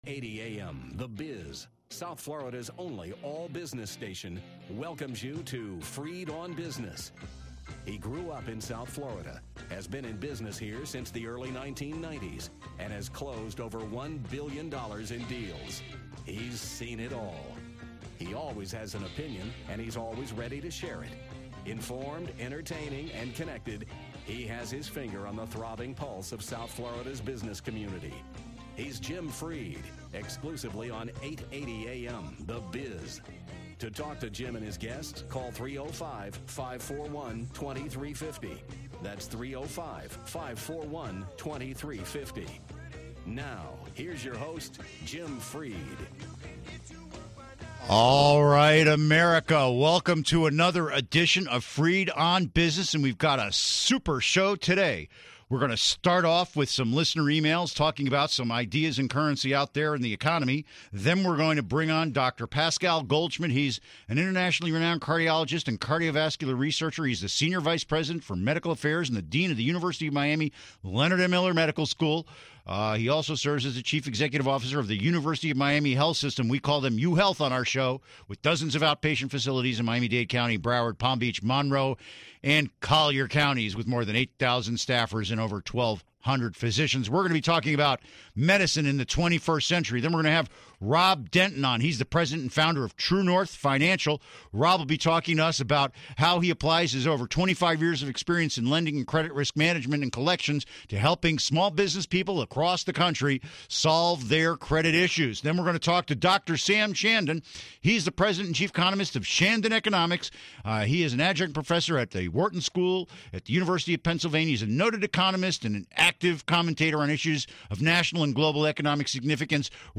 Listener E mails and Business Talk